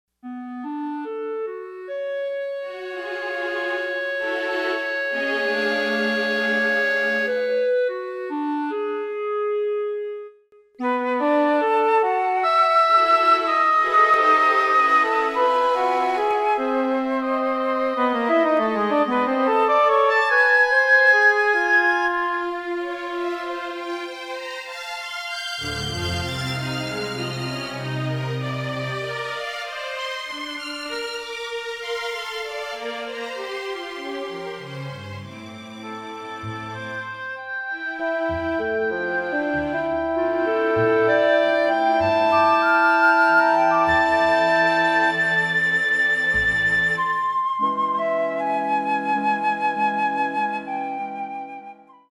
Concerto for English Horn and Orchestra